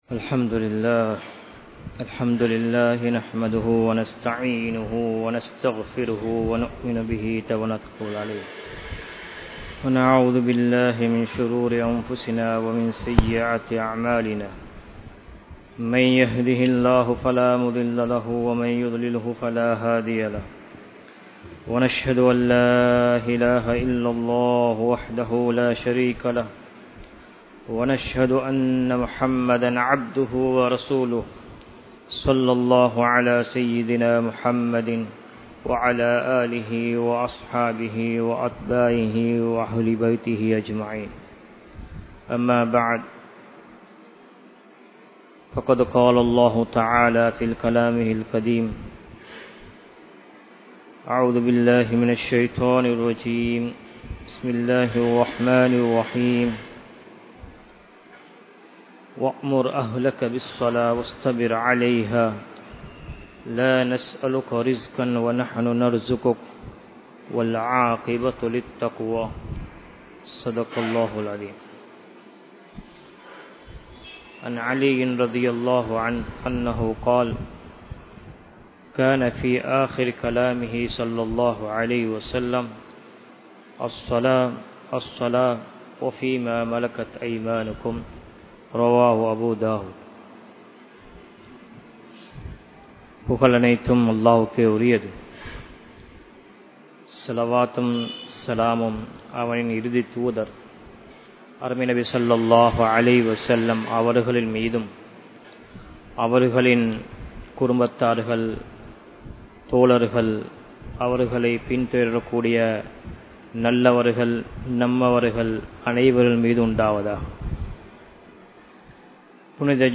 Vaalkaien Veattri (வாழ்க்கையின் வெற்றி) | Audio Bayans | All Ceylon Muslim Youth Community | Addalaichenai
Wellampittiya, Sedhawatte, Ar Rahmath Jumua Masjidh 2017-10-27 Tamil Download